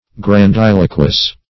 Search Result for " grandiloquous" : The Collaborative International Dictionary of English v.0.48: Grandiloquous \Gran*dil"o*quous\, a. [L. grandiloquus; grandis grand + loqui to apeak.]